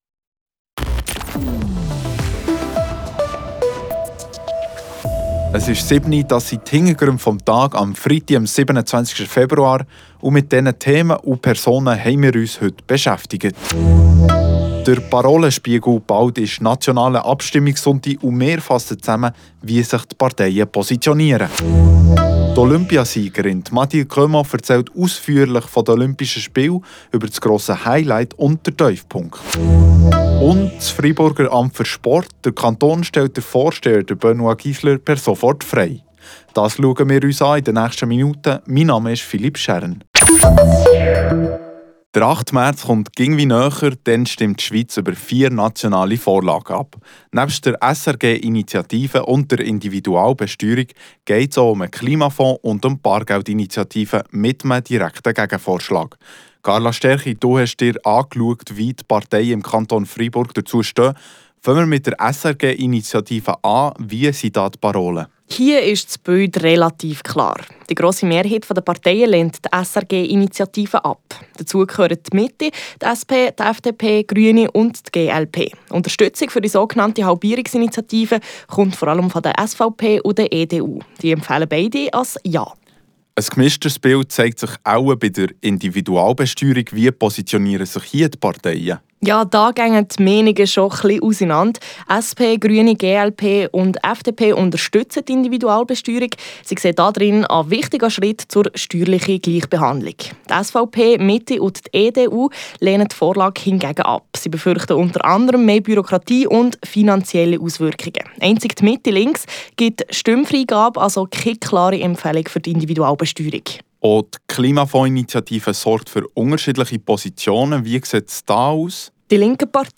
Beschreibung vor 1 Monat Der nationale Abstimmungssonntag steht bevor, wir fassen die Positionen der Parteien noch einmal zusammen: Wer stimmt bei welcher Vorlage Ja und wer setzt sich für ein Nein ein? Die Olympiasiegerin Mathilde Gremaud wurde gestern in La Roche empfangen. Sie hat uns erzählt, wie sie auf die Olympischen Spiele zurückblickt.